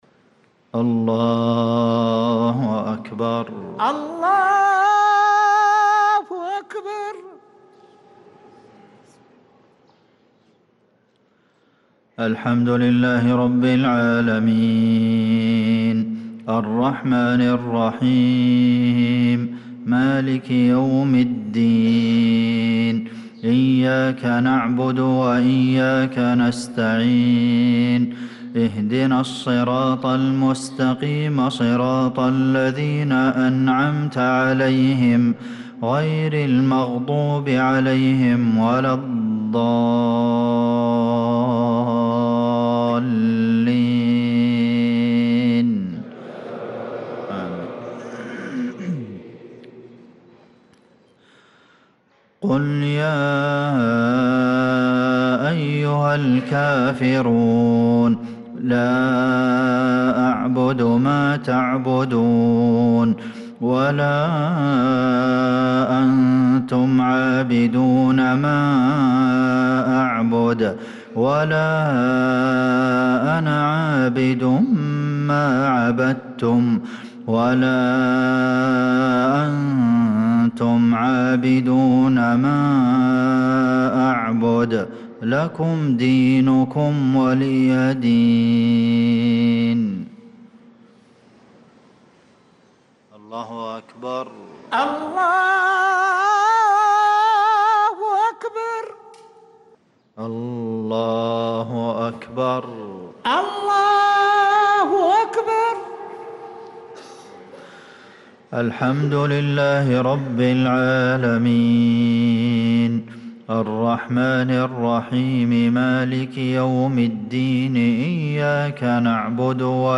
صلاة المغرب للقارئ عبدالمحسن القاسم 7 رجب 1445 هـ
تِلَاوَات الْحَرَمَيْن .